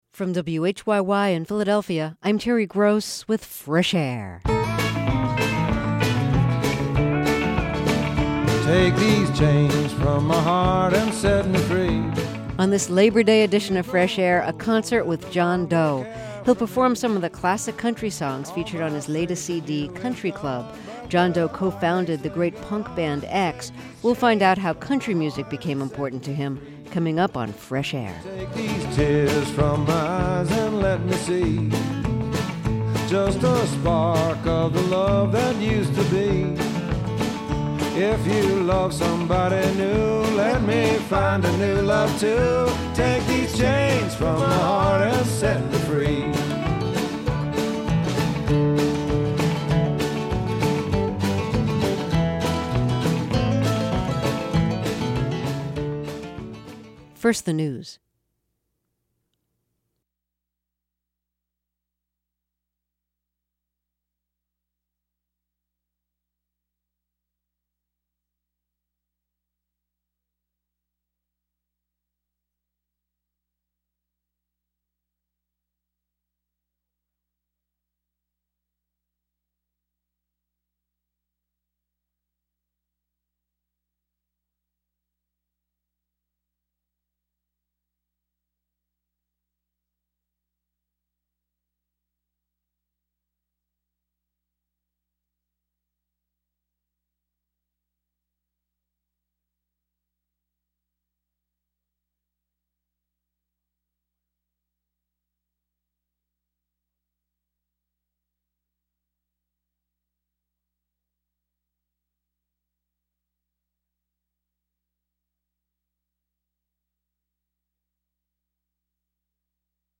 Terry Gross is the host and an executive producer of Fresh Air, the daily program of interviews and reviews.